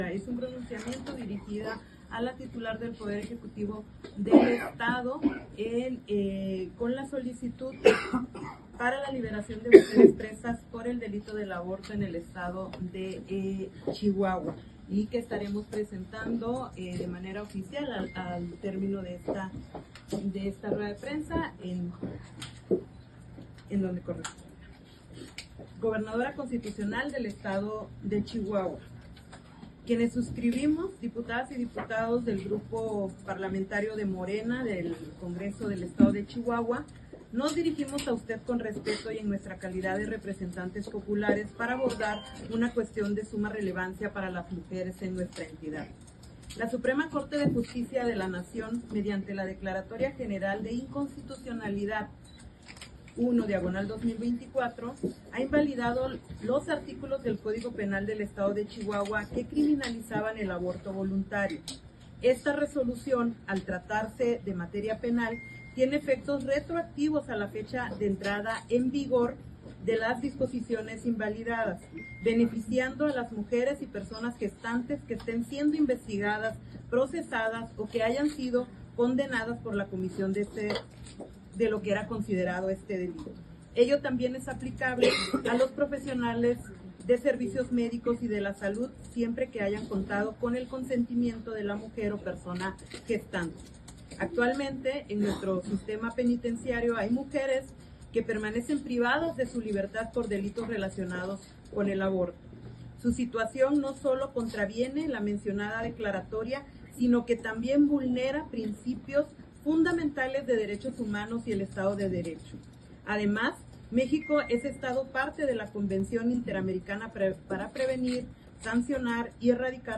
Noticias Chihuahua: Noticias de Chihuahua La presidenta del Congreso Elizabeth Guzmán, propuso solicitar a la gobernadora del estado María Eugenia Campos se libere a las mujeres que fueron encarceladas por practicarse un aborto. En la rueda de prensa del Grupo Parlamentario de Morena, la dirigente del legislativo, leyó el documento que presentará ante el resto de los diputados para que se apruebe formalmente y se envíe al poder ejecutivo estatal.